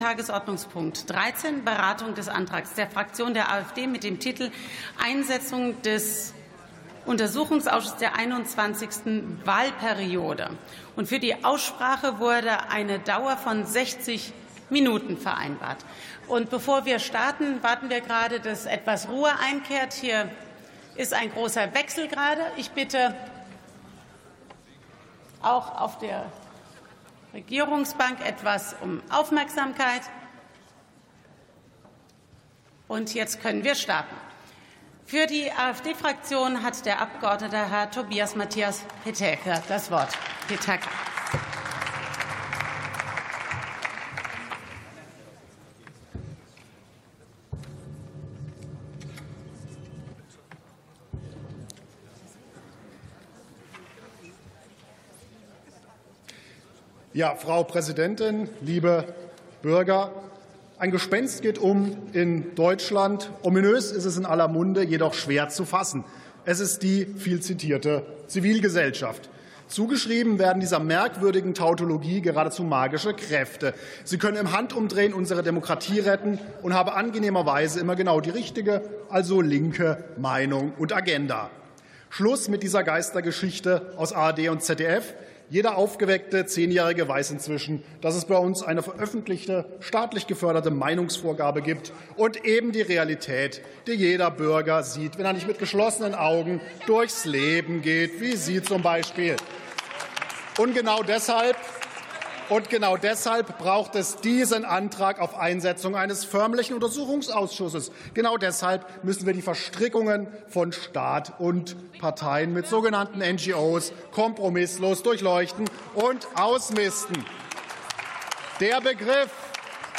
WP 1 Stunde 24 Minuten 0 B Podcast Podcaster Plenarsitzungen - Audio Podcasts Deutscher Bundestag Nachrichten & Politik Podcast aneignen Beschreibung vor 1 Tag Einsetzung eines UA in der 21.